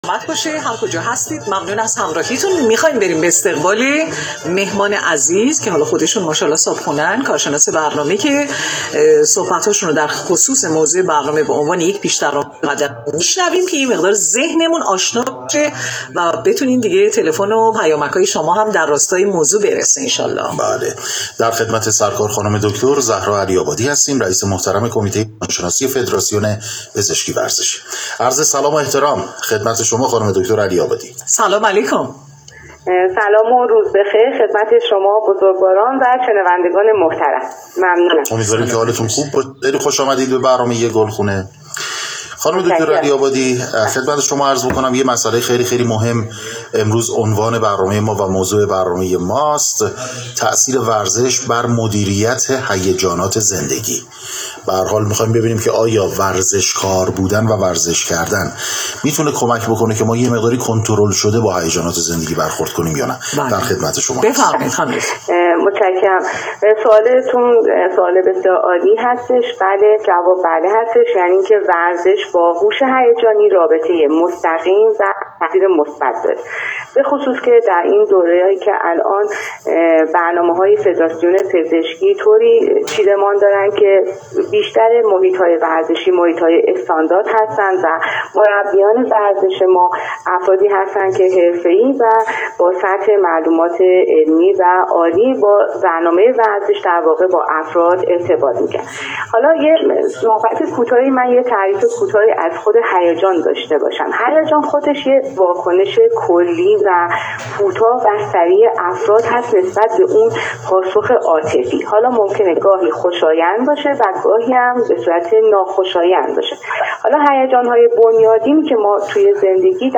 /مصاحبه/